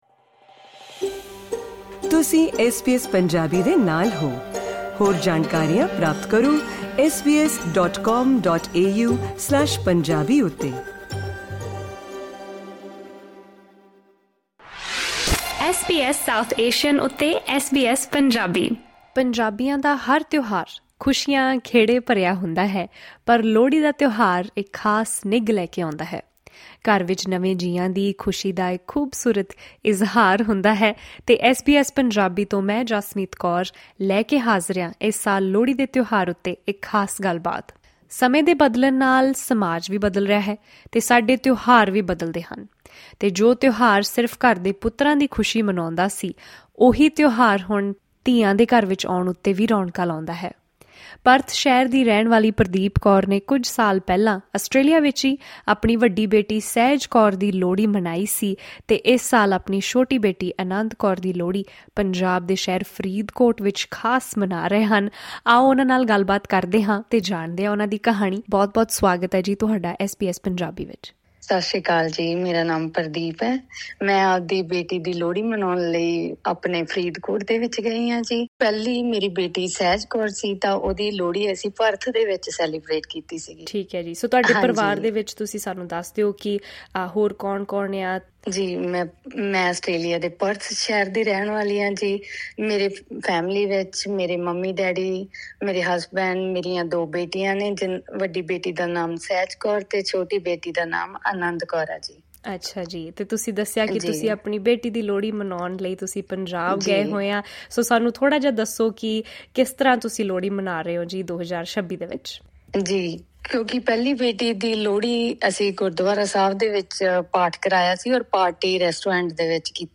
ਆਓ ਸੁਣਦੇ ਹਾਂ ਉਨ੍ਹਾਂ ਨਾਲ ਕੀਤੀ ਪੂਰੀ ਗੱਲਬਾਤ।